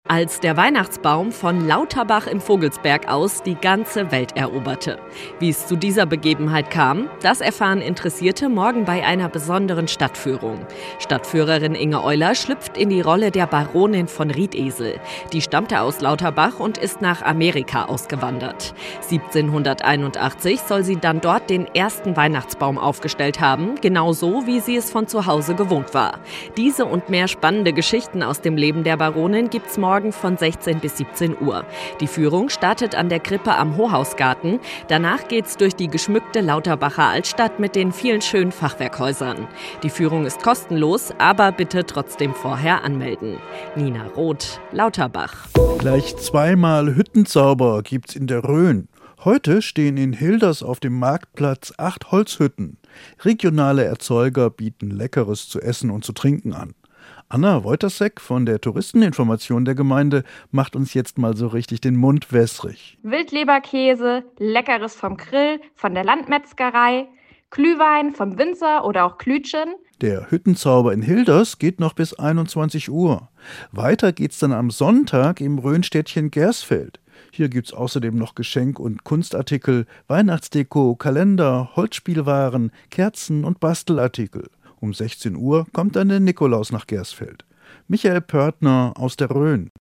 Mittags eine aktuelle Reportage des Studios Fulda für die Region